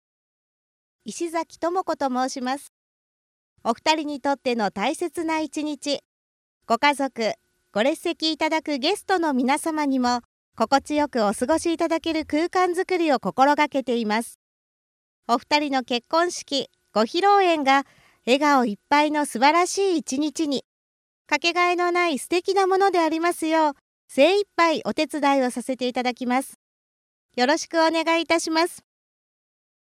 よく通る元気な声ではつらつとした司会が好印象。